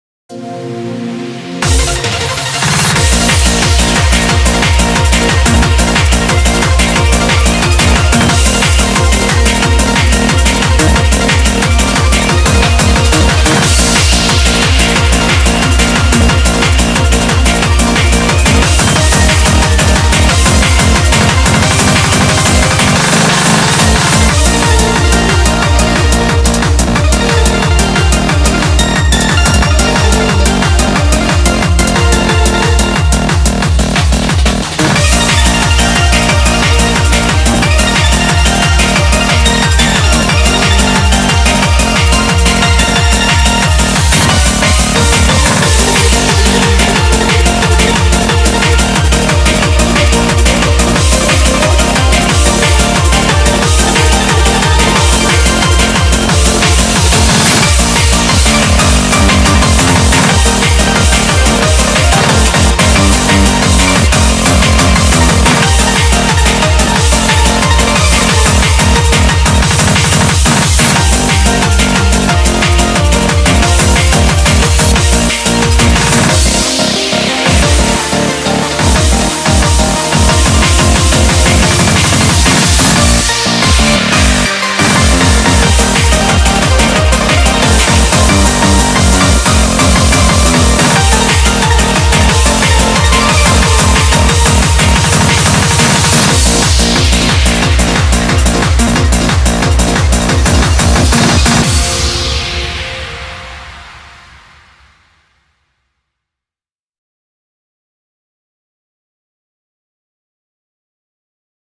BPM180